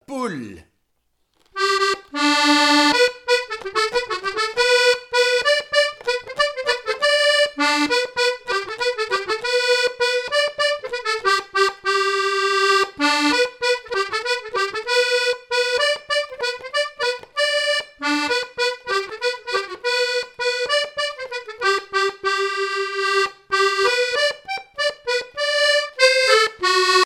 danse : quadrille : poule
Répertoire à l'accordéon diatonique
Pièce musicale inédite